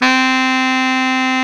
SAX B.SAX 1A.wav